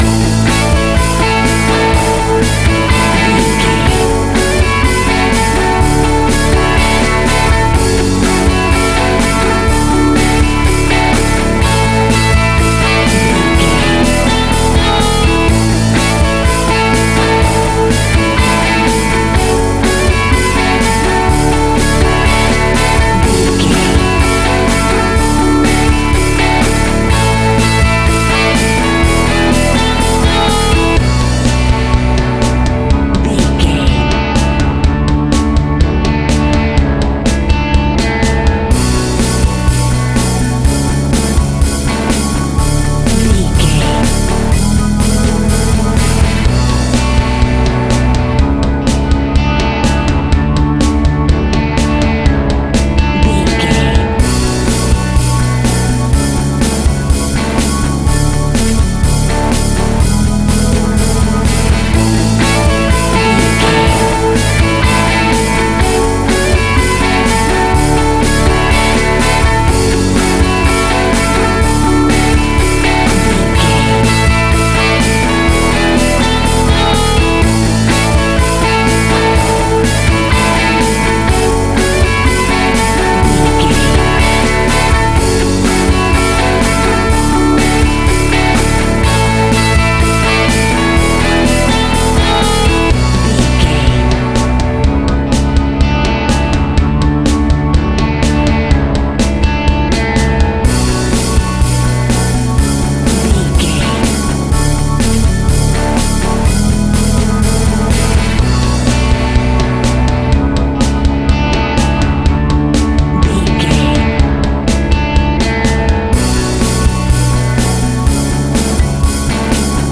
Huge Pop Rock Theme.
Fast paced
Ionian/Major
happy
uplifting
drums
bass guitar
electric guitar
synth keys